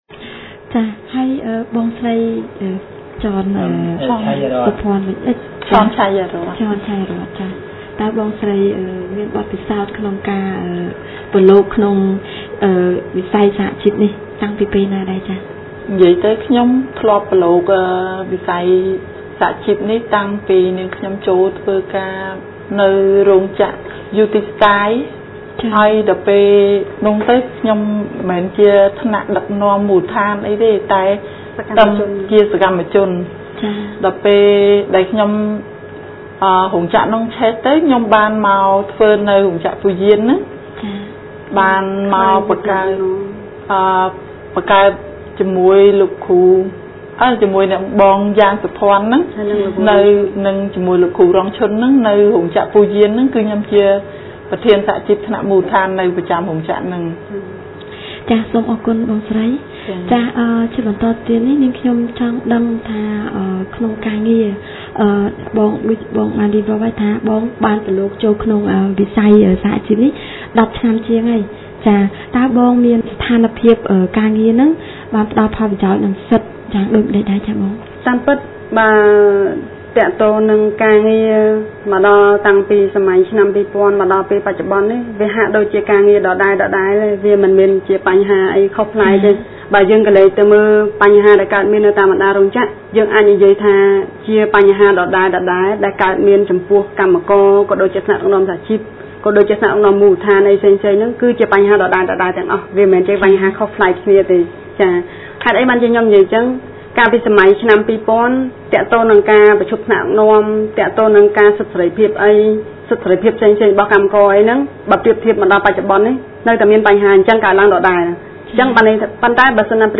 The purpose of the radio talk show is to discuss issues of practice of human rights and human rights violations by businesses in the garment sector in Cambodia.